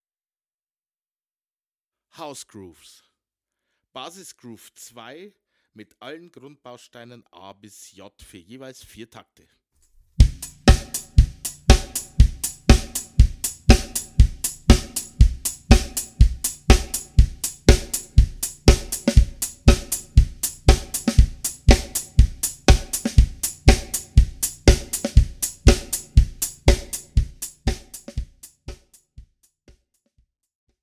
Besetzung: Schlagzeug
05 - House-Groove 1
House-Grooves